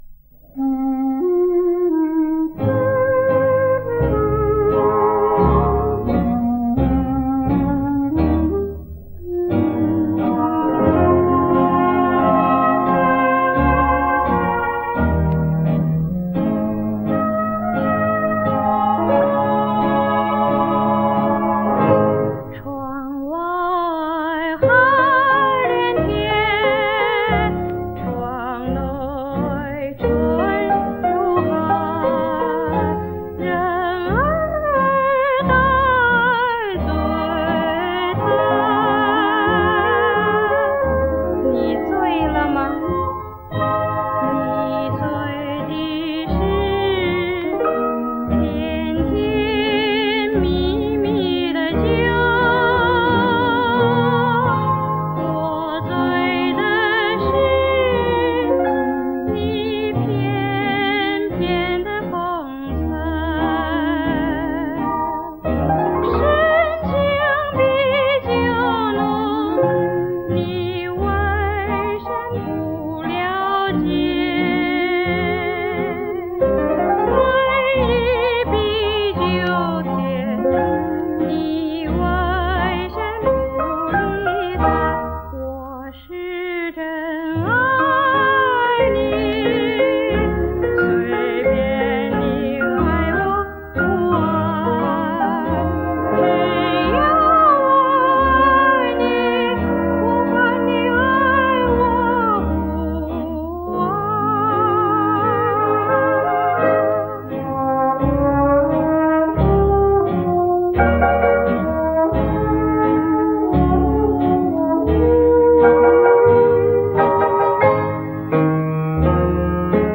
我这个“音质”的说法，是以考虑到歌曲都是几十年前的录音来衡量。